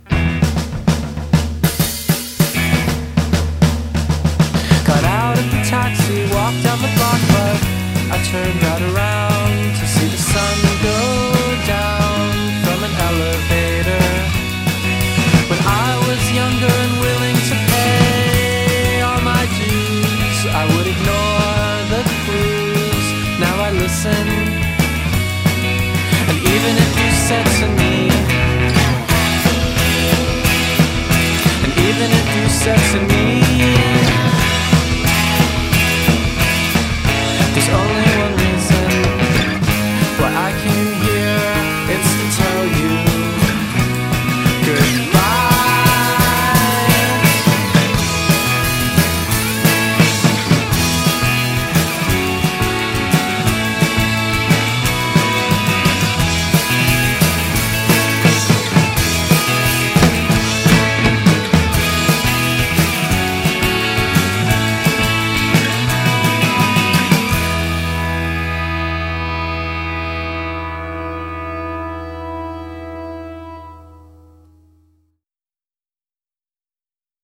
centoventi secondi frenetici e smithsiani